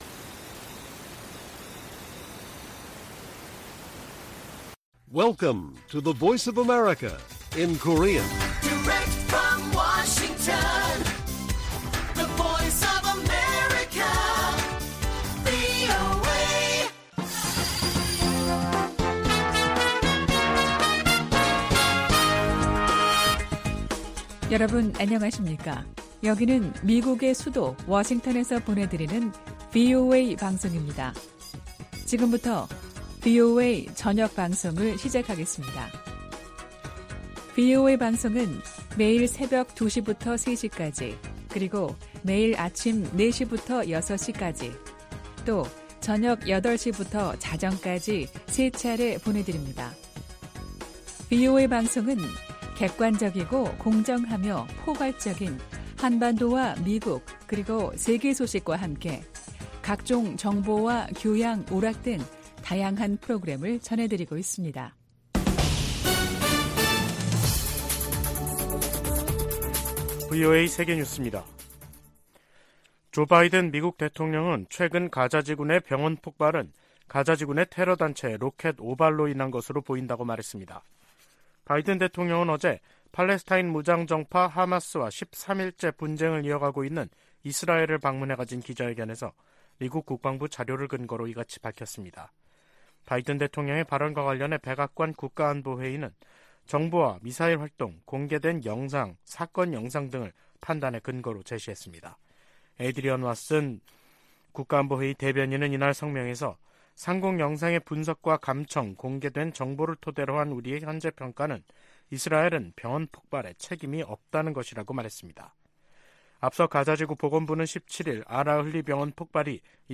VOA 한국어 간판 뉴스 프로그램 '뉴스 투데이', 2023년 10월 19일 1부 방송입니다. 북한을 방문한 세르게이 라브로프 러시아 외무장관은 양국 관계가 질적으로 새롭고 전략적인 수준에 이르렀다고 말했습니다. 미 상원의원들은 북-러 군사 협력이 러시아의 우크라이나 침략 전쟁을 장기화하고, 북한의 탄도미사일 프로그램을 강화할 수 있다고 우려했습니다. 북한이 암호화폐 해킹을 통해 미사일 프로그램 진전 자금을 조달하고 있다고 백악관 고위 관리가 밝혔습니다.